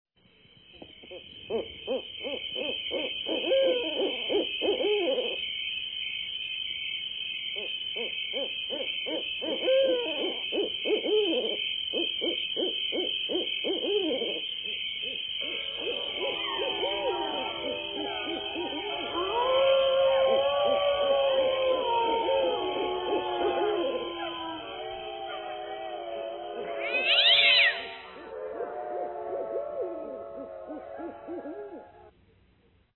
Owls, crickets, cats, howls